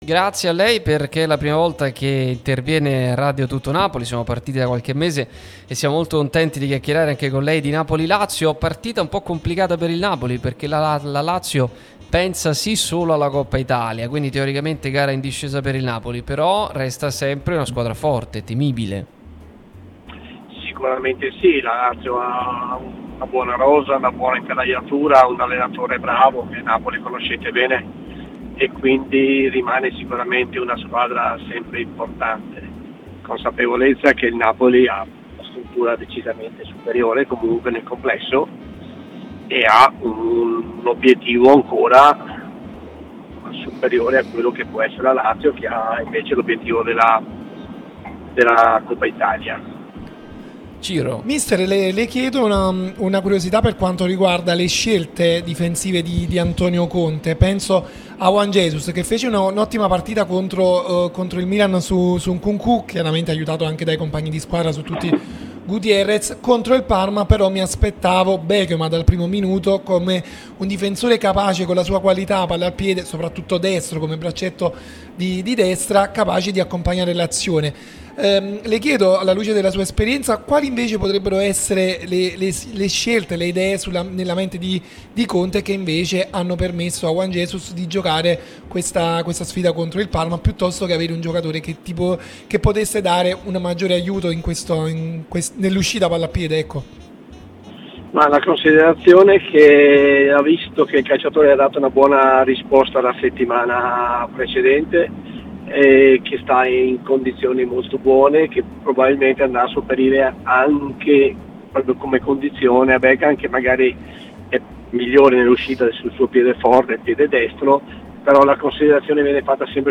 Attilio Tesser, allenatore, è intervenuto su Radio Tutto Napoli, l'unica radio tutta azzurra e live tutto il giorno.